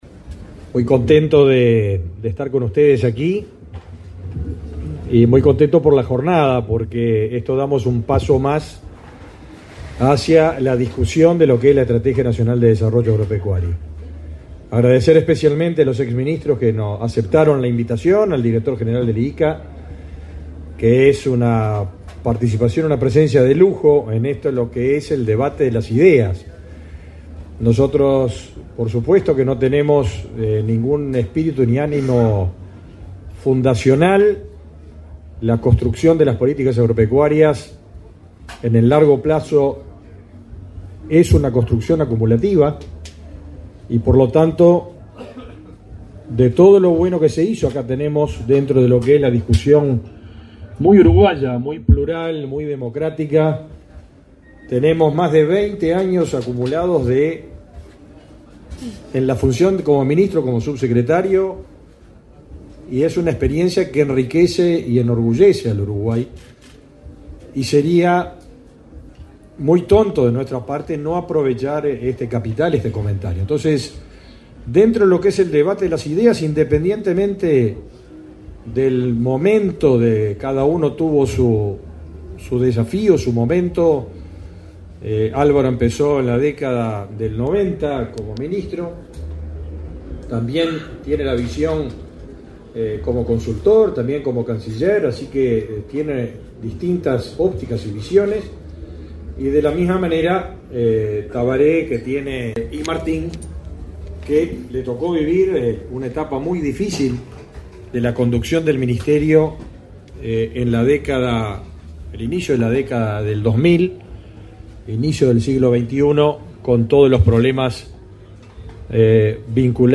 Palabras del ministro de Ganadería, Agricultura y Pesca, Fernando Mattos
El ministro de Ganadería, Agricultura y Pesca, Mattos, realizó, el 12 de setiembre, la presentación del Plan Estratégico para el Agro y la Acuicultura